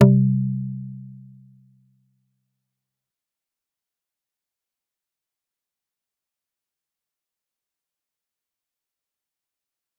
G_Kalimba-C3-f.wav